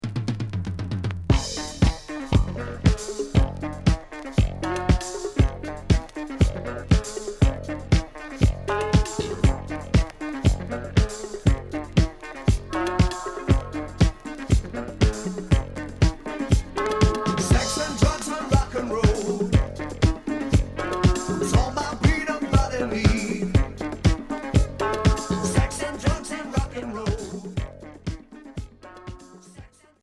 Groove rock